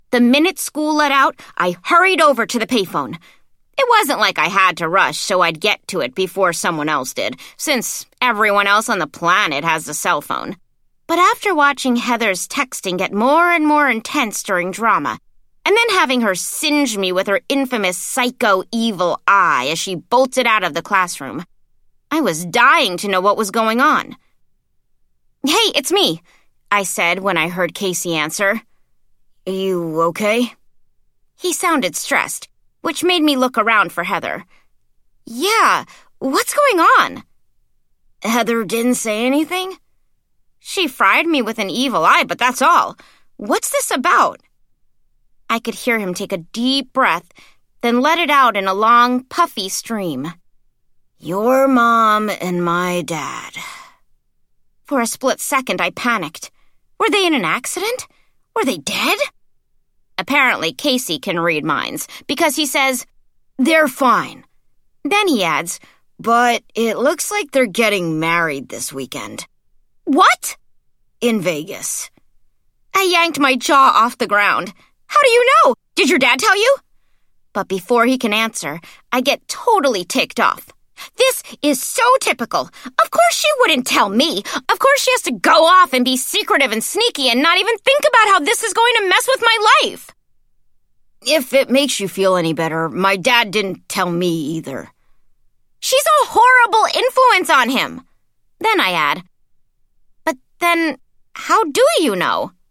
Home  >  Middle Grade Audiobooks